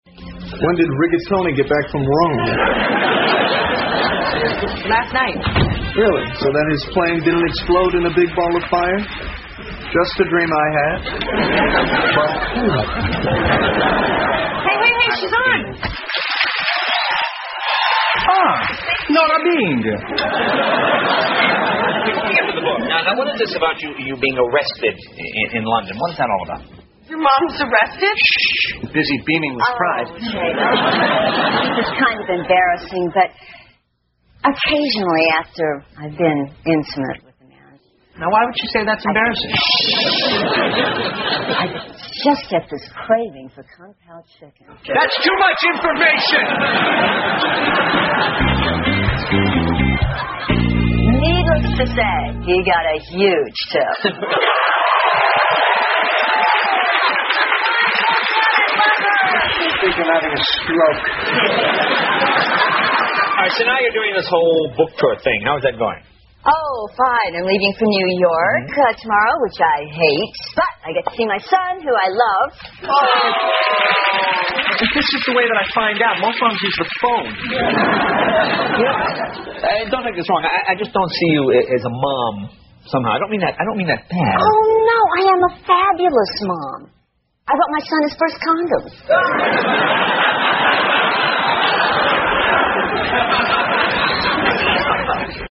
在线英语听力室老友记精校版第1季 第129期:钱德之母(3)的听力文件下载, 《老友记精校版》是美国乃至全世界最受欢迎的情景喜剧，一共拍摄了10季，以其幽默的对白和与现实生活的贴近吸引了无数的观众，精校版栏目搭配高音质音频与同步双语字幕，是练习提升英语听力水平，积累英语知识的好帮手。